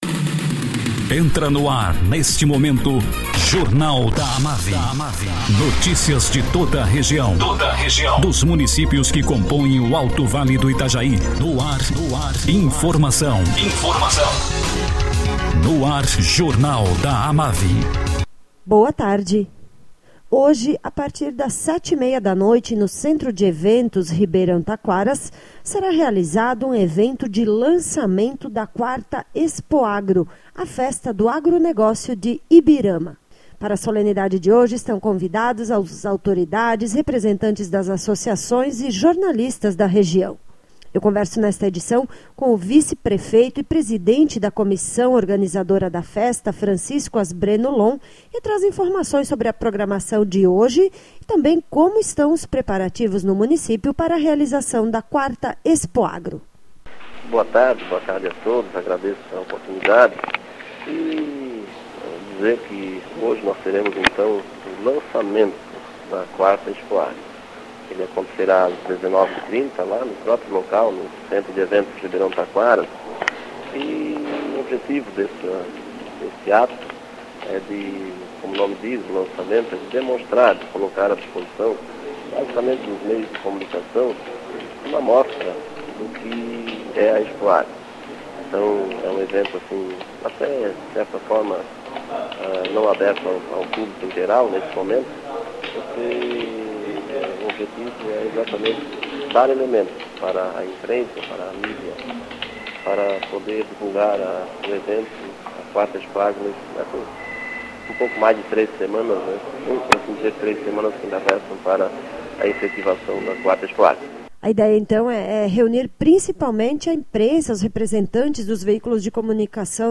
Vice-prefeito de Ibirama e presidente da comissão organizadora da 4ª Expoagro, Francisco Asbreno Lohn, fala sobre o lançamento da festa que acontece hoje, às 19h30min no Centro de Eventos Ribeirão Taquaras.